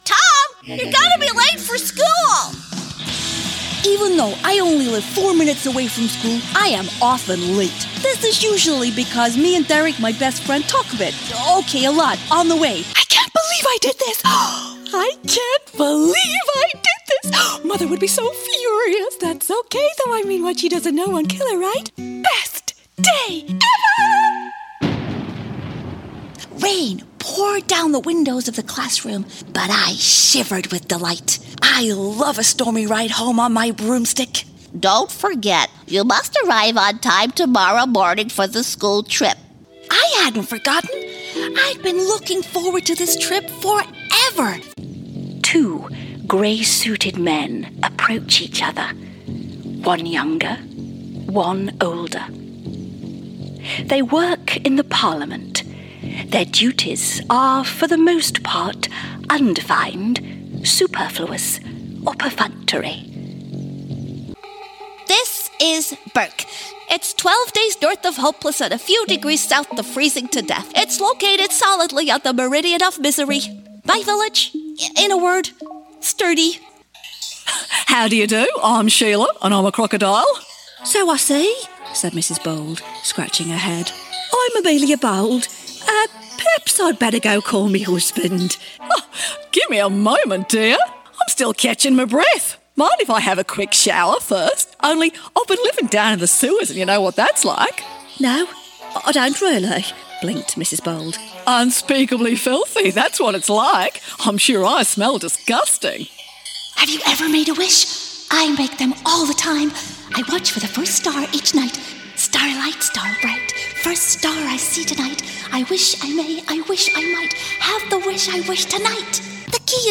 Voix off
Comédienne anglaise, beaucoup d'expérience théâtrale, des publicité, des voix off, de la formation.